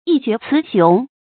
一決雌雄 注音： ㄧ ㄐㄩㄝˊ ㄘㄧˊ ㄒㄩㄥˊ 讀音讀法： 意思解釋： 雌雄：比喻勝負；高下。決定勝敗高低。